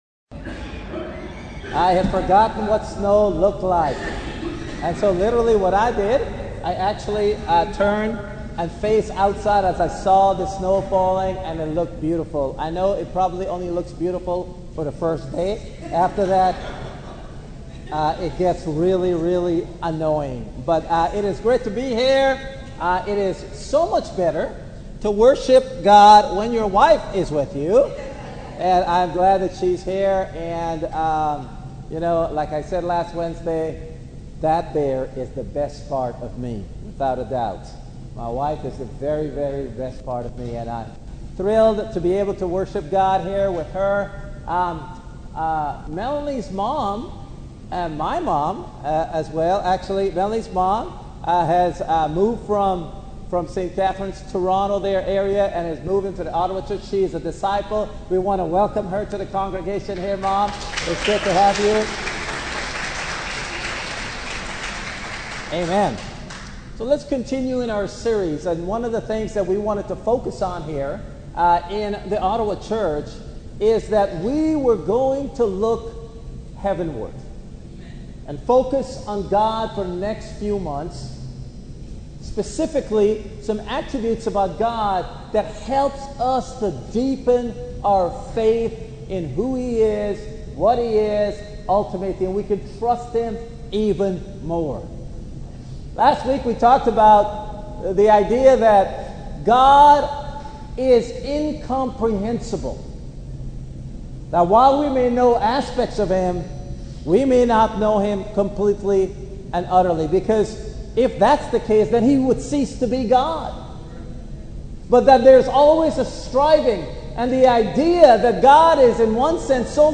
Bible Verses from the sermon